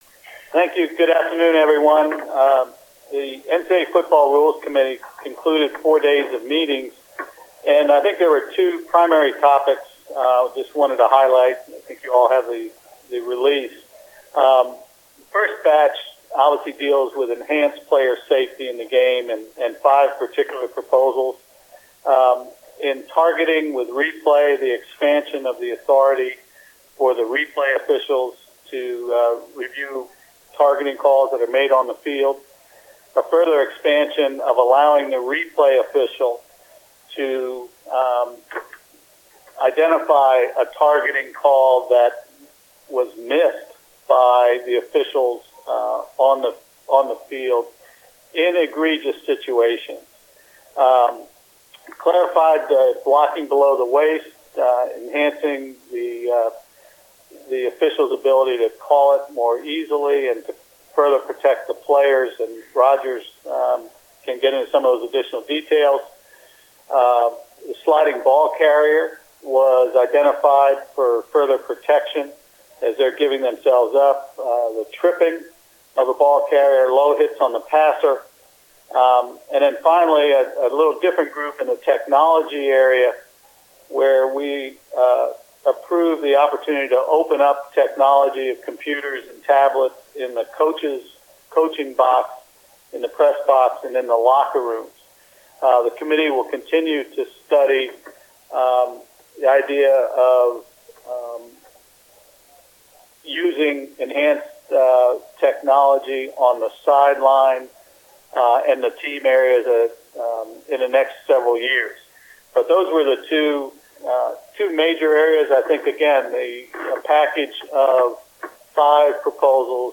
NCAA Football Rules Committee 2016 Annual Meeting Media Teleconference